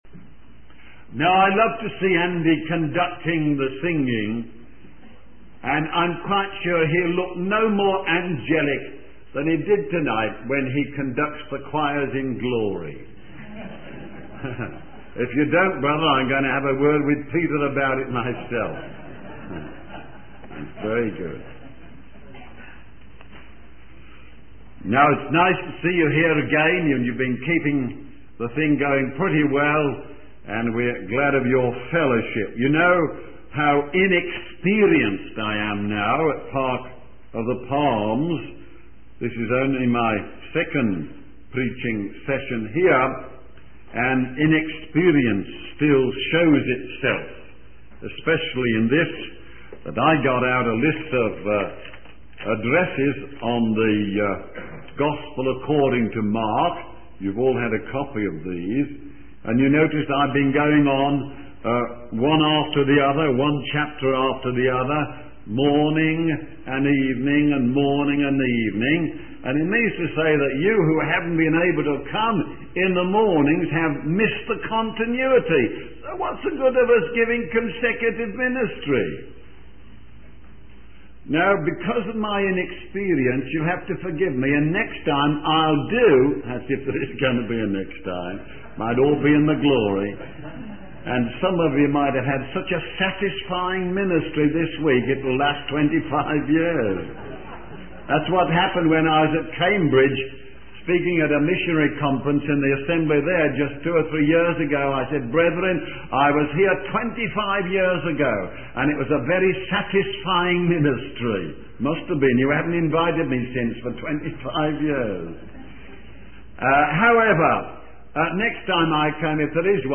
In this sermon, the preacher shares a humorous anecdote about a preacher who unknowingly preached to an empty congregation. The sermon then focuses on the idea of the 'second touch' as seen in Mark 8.